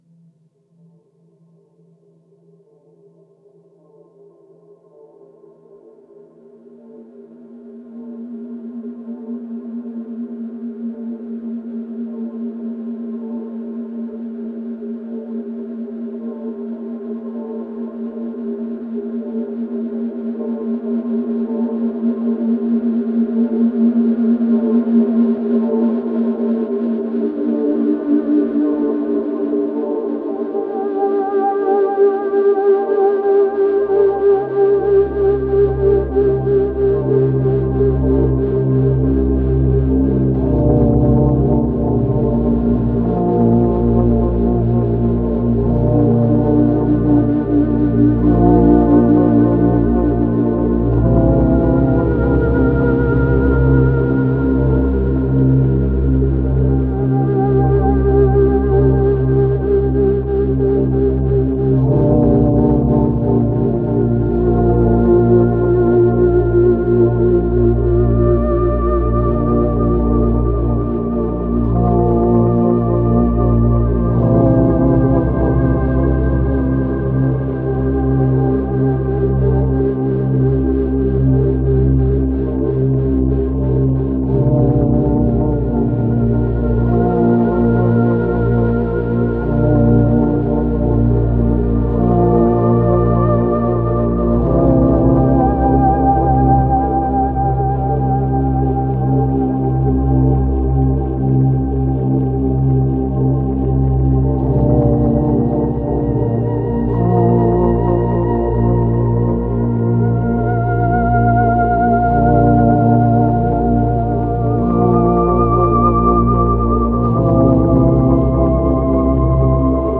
Genre: Relax, Meditation, Ambient, New Age, Ambient.